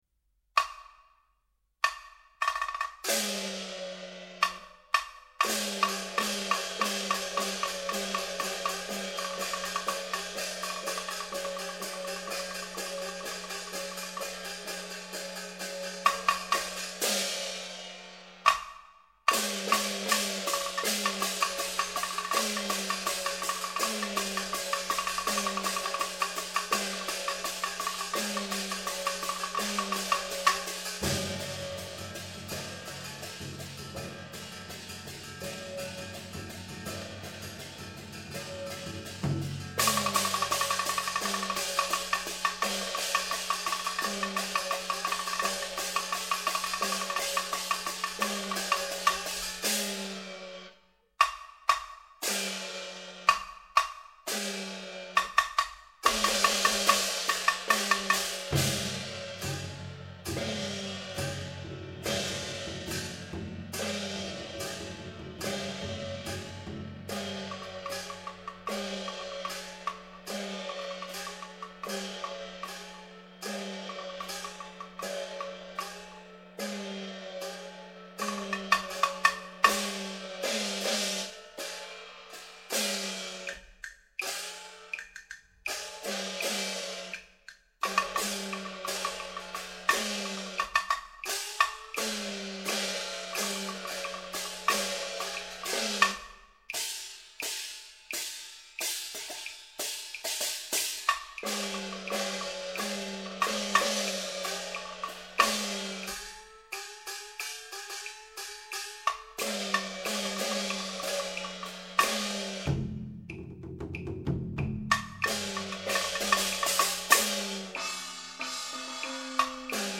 苏南十番锣鼓